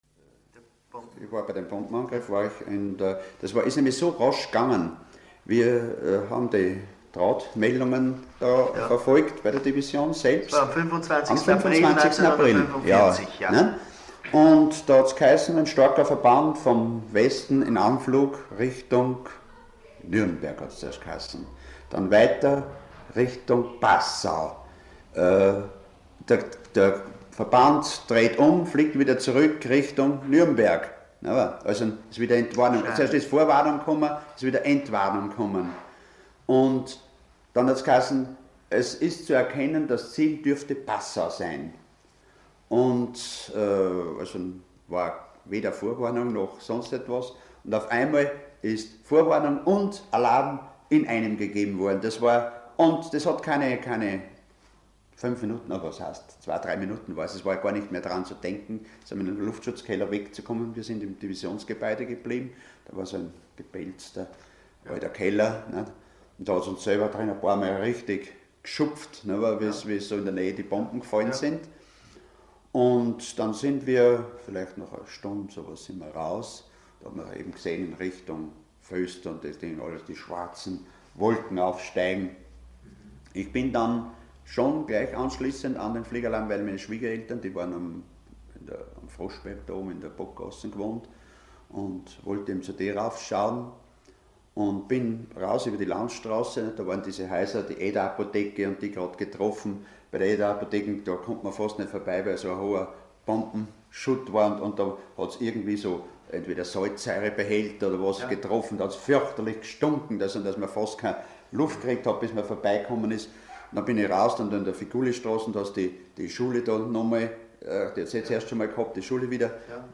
Interview mit Gemeinderat Fred Tautermann
Ausschnitt aus einem Interview mit Gemeinderat Fred Tautermann über seine Erlebnisse aus dem Jahr 1945. Er berichtet darin über den letzten großen Luftangriff vom 25. April 1945.